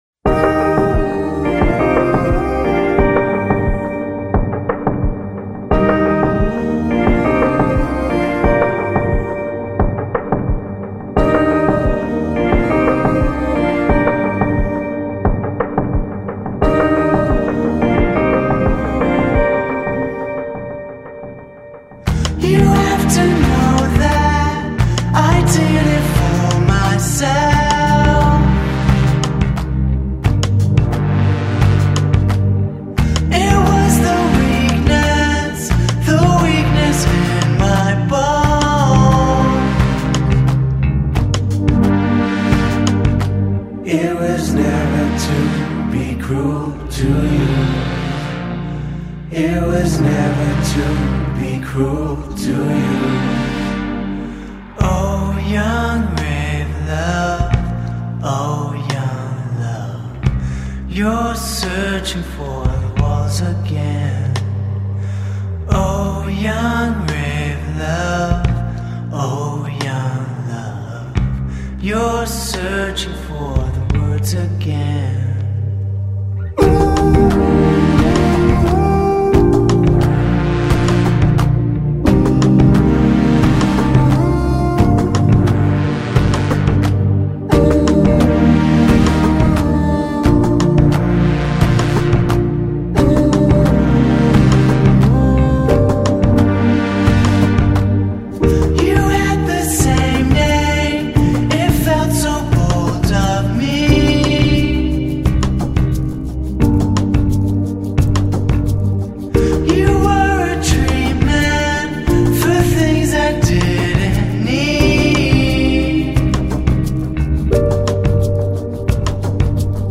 Brooklyn-based electronic downtempo trio